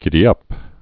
(gĭdē-ŭp) also gid·dy·ap (-ăp, -ŭp) or gid·dap (gĭ-dăp, -dŭp)